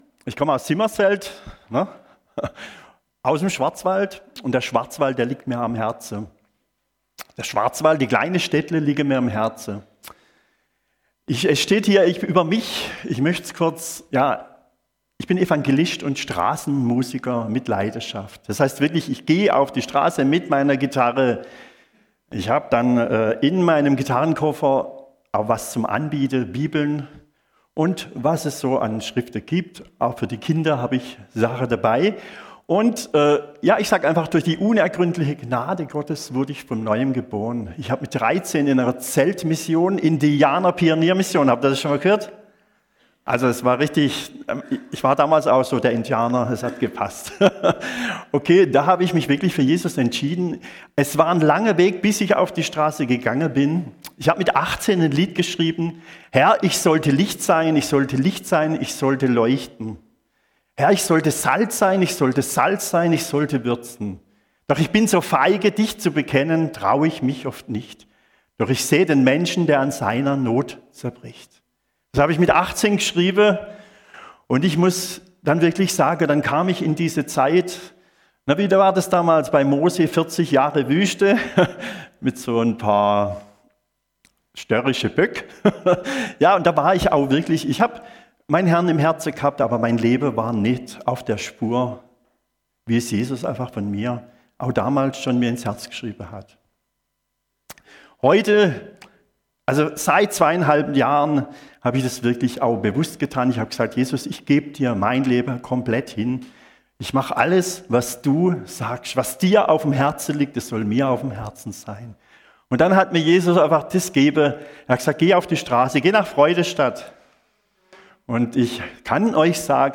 Predigt & Zeugnis